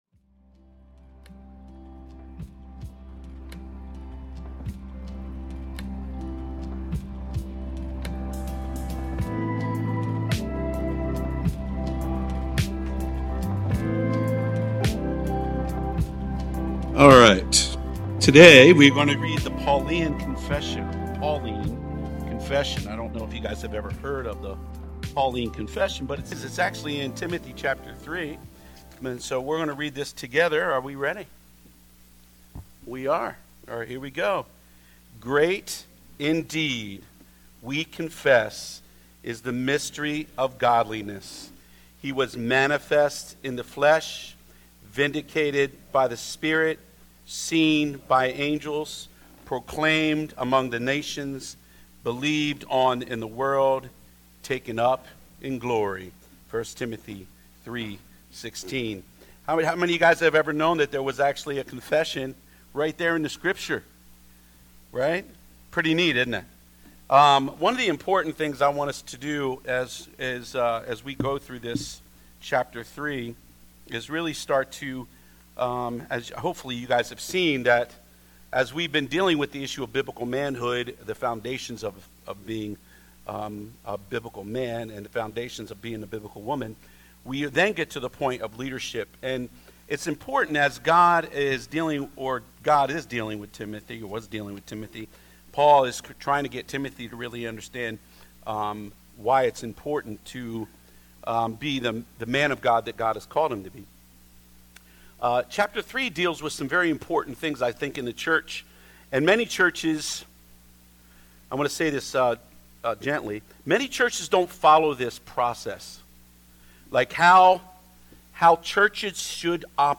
Sermons | Mount Eaton Church